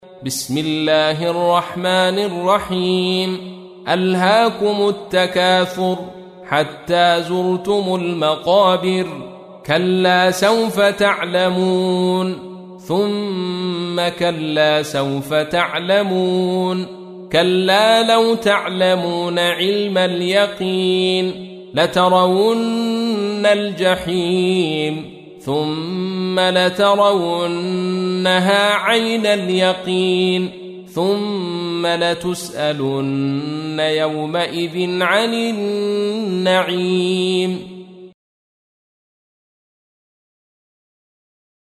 تحميل : 102. سورة التكاثر / القارئ عبد الرشيد صوفي / القرآن الكريم / موقع يا حسين